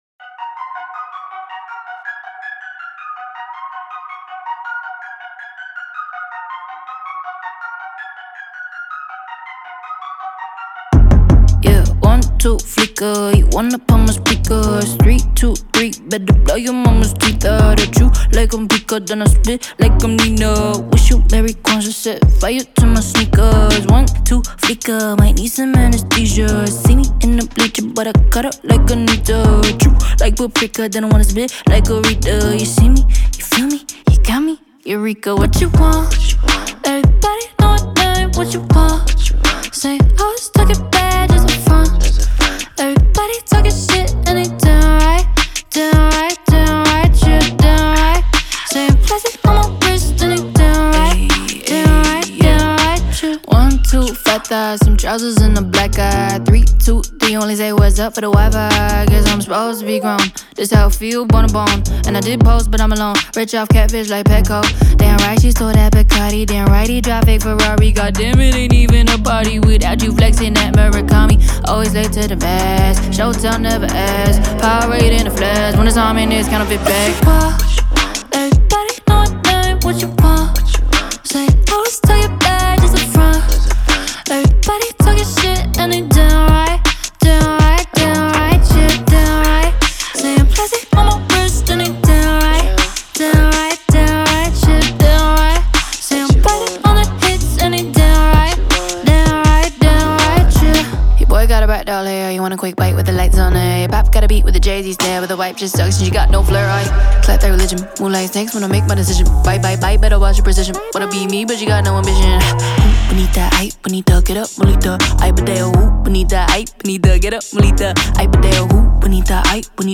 BPM81-81
Audio QualityPerfect (High Quality)
Trap song for StepMania, ITGmania, Project Outfox
Full Length Song (not arcade length cut)